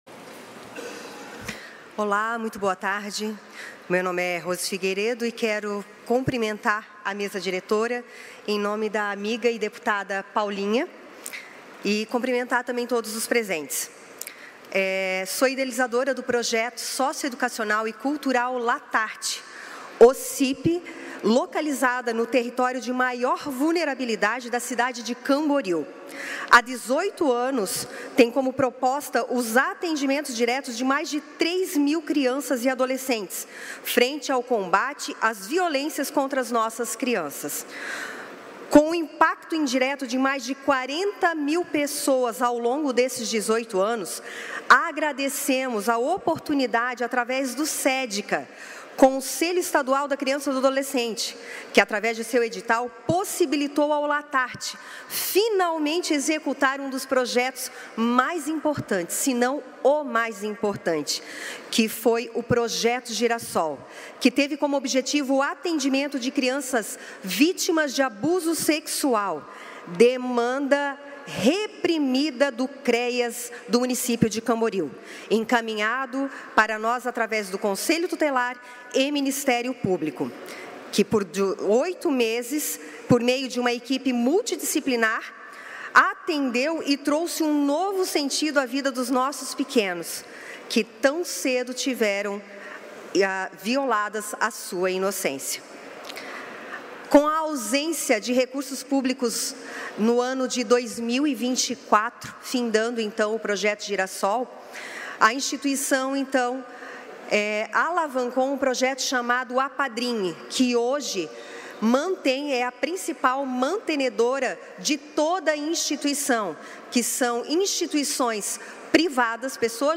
Pronunciamentos das entidades do Vale do Itajaí na sessão ordinária desta quarta-feira (08)